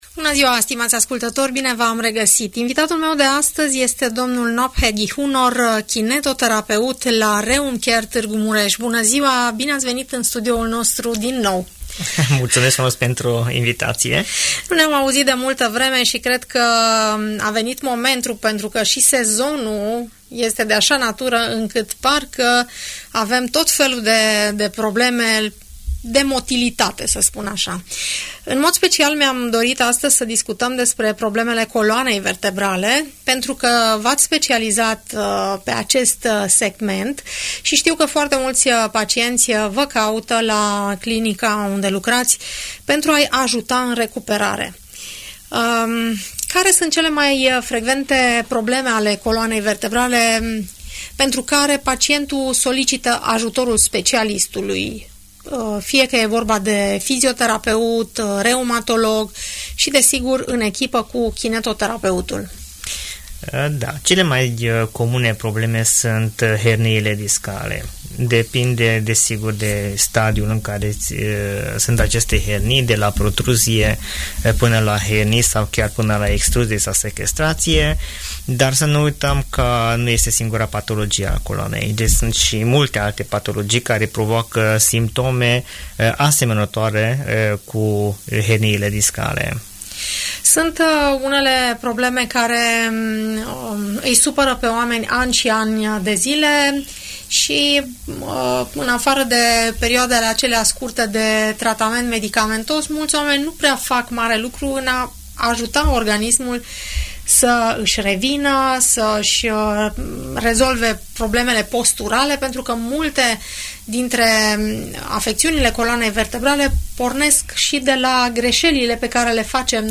dialogul moderat